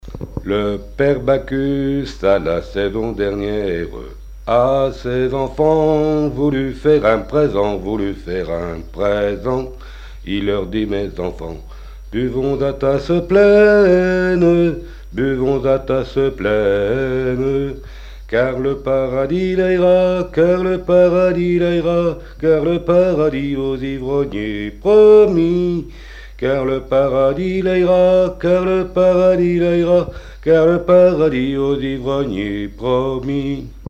un couplet et le refrain seulement
bachique
une chanson populaire et traditionnelle
Pièce musicale inédite